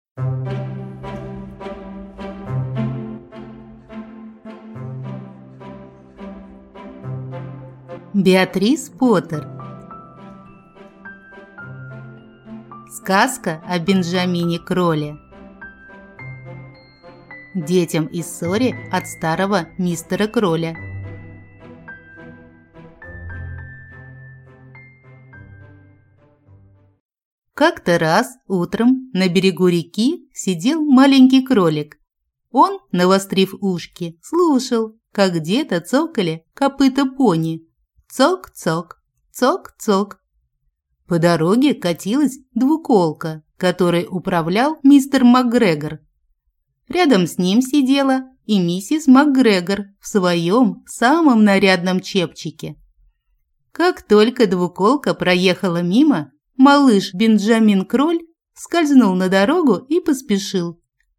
Аудиокнига Сказка о Бенджамине Кроле | Библиотека аудиокниг